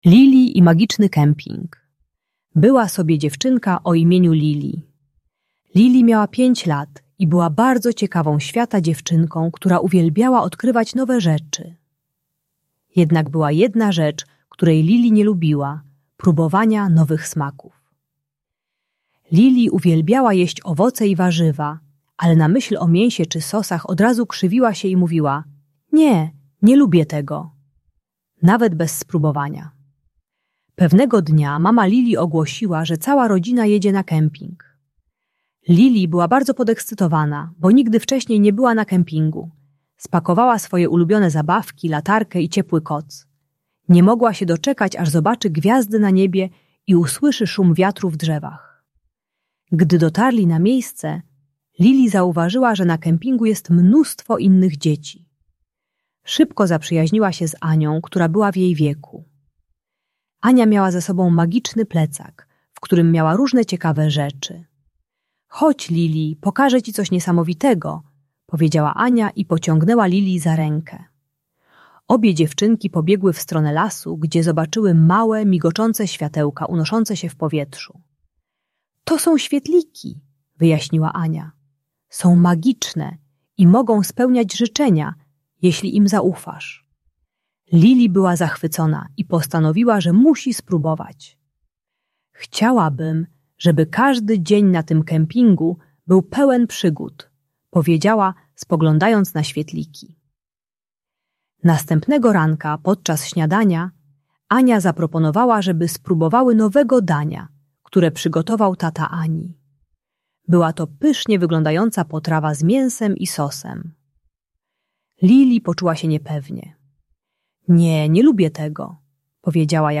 Lili i Magiczny Kemping - Problemy z jedzeniem | Audiobajka